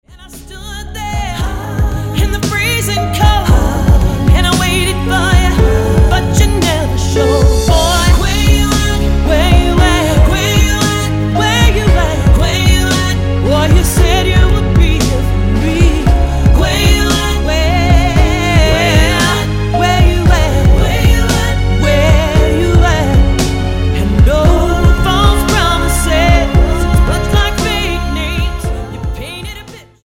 NOTE: Vocal Tracks 10 Thru 18
W/ Vocals